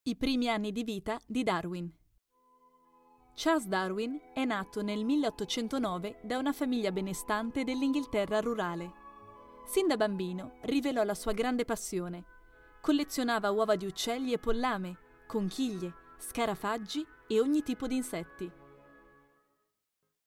Italian professional voice over, bright, energetic, friendly and dynamic.
Sprechprobe: eLearning (Muttersprache):